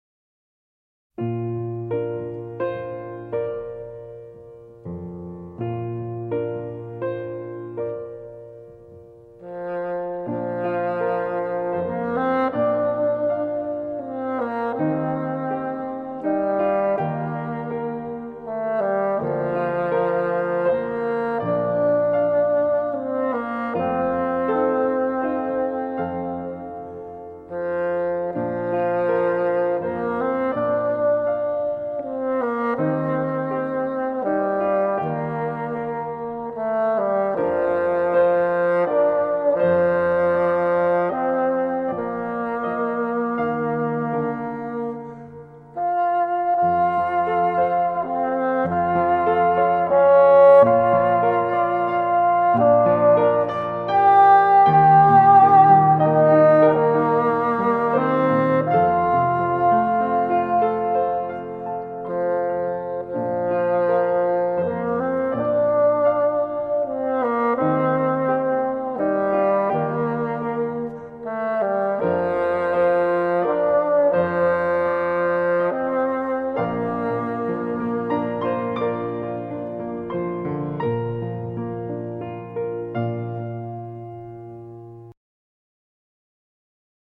癒しの音楽